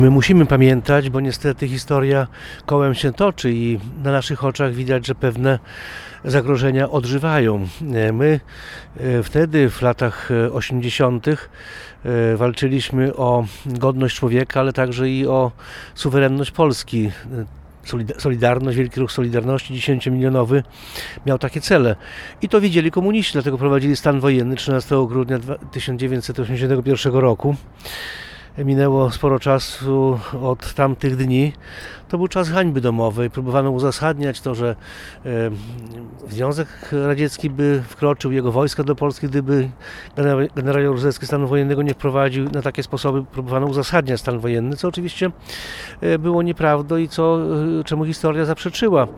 Obchody 43. rocznicy wprowadzenia stanu wojennego w Polsce odbyły się w piątek (13.12.24) w Suwałkach.
– To był czas hańby – mówi Jarosław Zieliński, poseł Prawa i Sprawiedliwości.